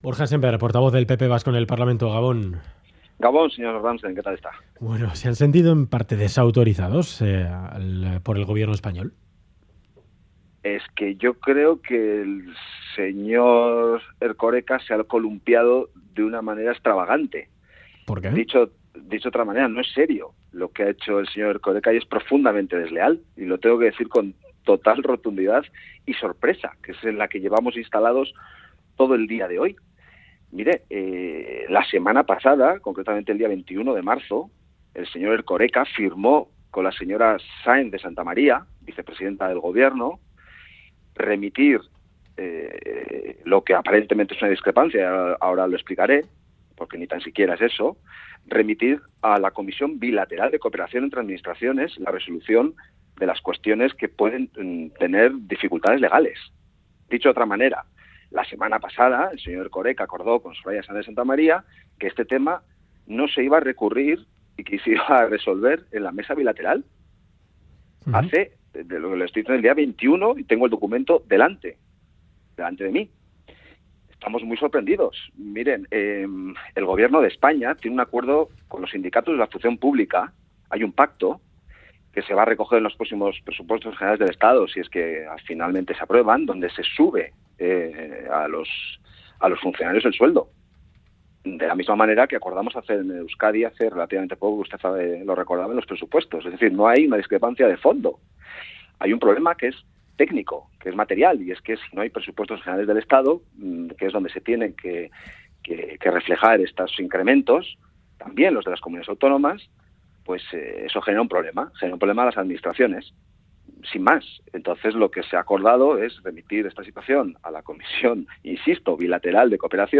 Radio Euskadi GANBARA 'No hay amenaza alguna, no hay voluntad de recurrir' Última actualización: 27/03/2018 22:53 (UTC+2) Entrevista a Borja Sémper, presidente del PP de Gipuzkoa y portavoz popular en el Parlamento Vasco.